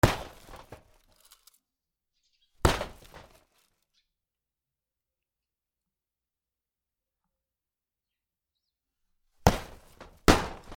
粗大ゴミを蹴る
/ H｜バトル・武器・破壊 / H-35 ｜打撃・衝撃・破壊　強_ナチュラル寄り